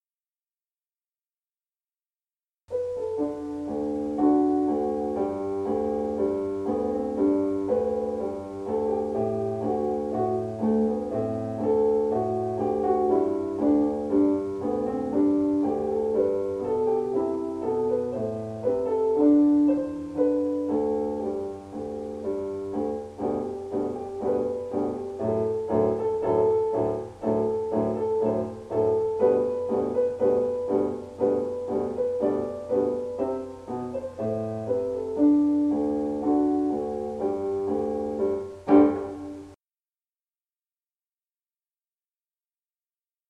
Have your student sing with the piano part
Hill-School-Song-Piano-Only.mp3